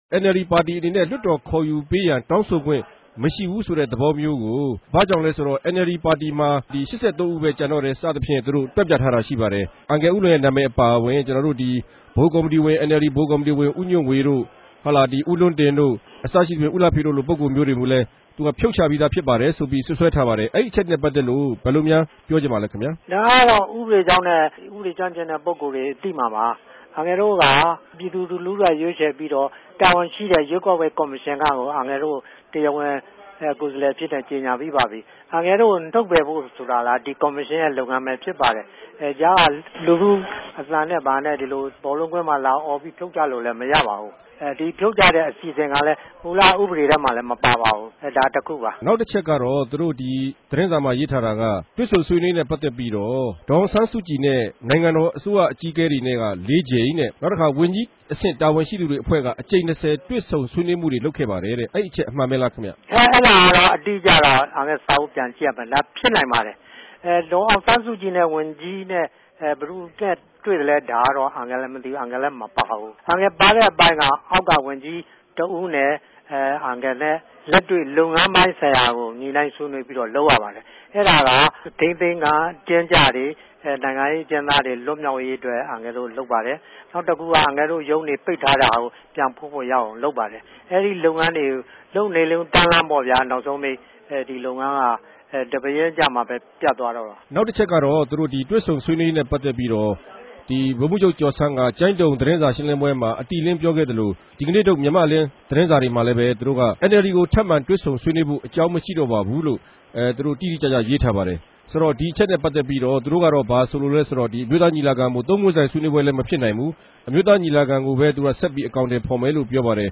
ထိုင်းိံိုင်ငံ ဗန်ကောက်္ဘမိြႚကနေ ဆက်သြယ် မေးူမန်းထားတာကို နားထောငိံိုင်ပၝတယ်။